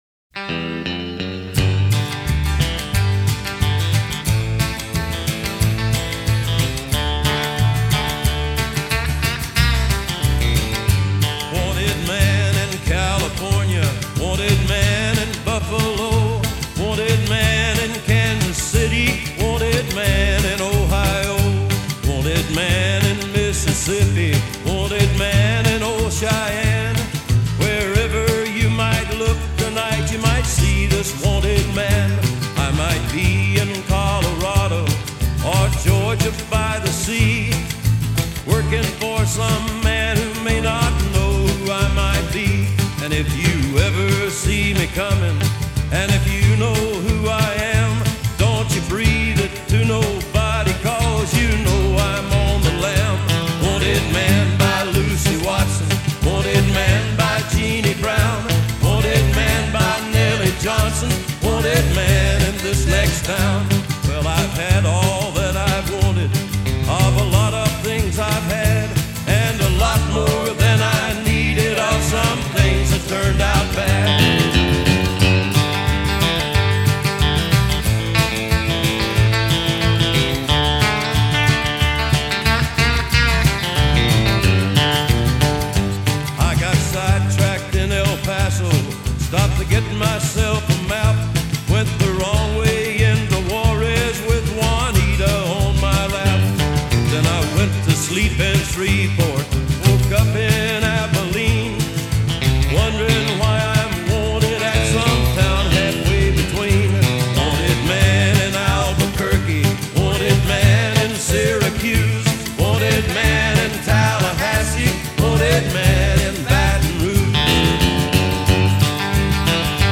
Жанр: Country, Rock